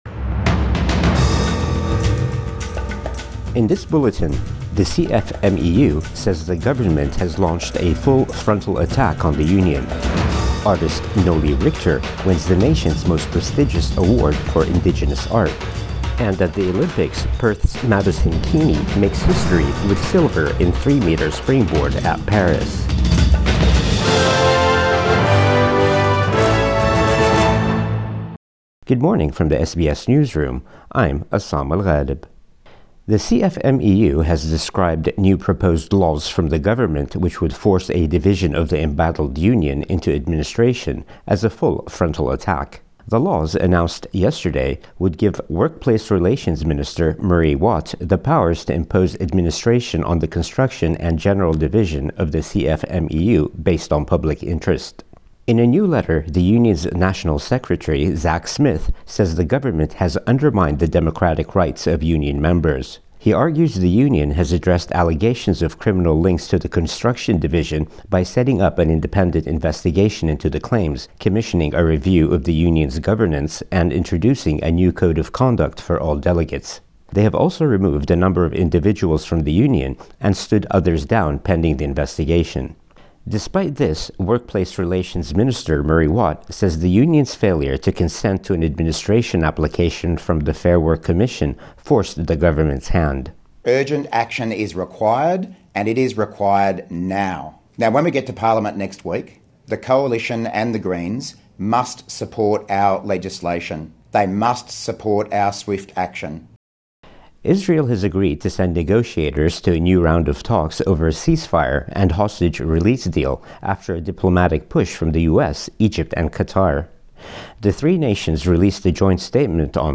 Morning News Bulletin 10 August 2024